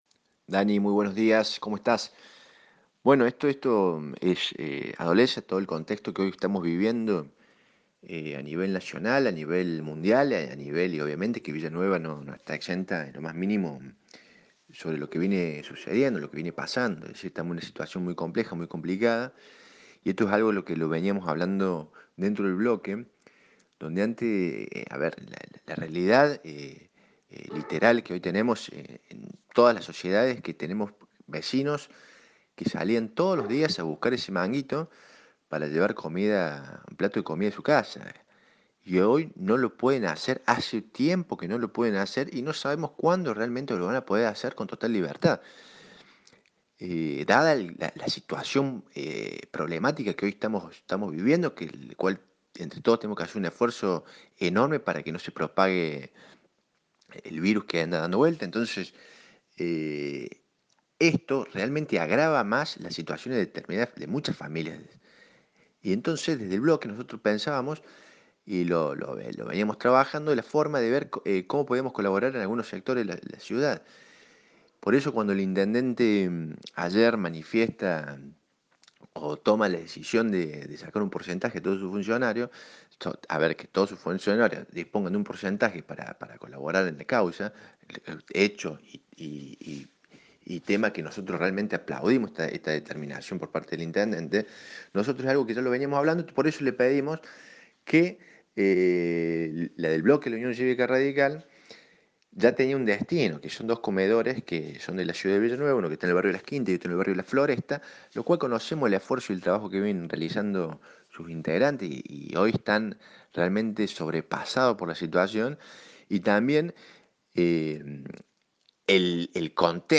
Ignacio Tagni, en diálogo con Radio Show explicó la determinación de donar la mitad de sus salarios a dos comedores de la ciudad de Villa Nueva.